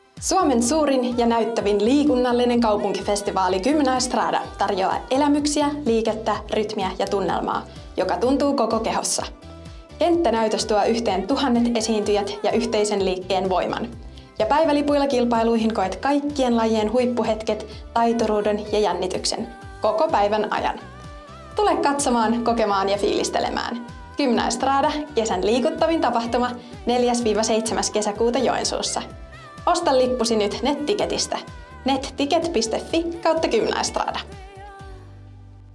Gymnaestrada-äänimainos 2 - lipunmyynti - Materiaalipankki
Gymnaestrada-aanimainos-2-lipunmyynti.mp3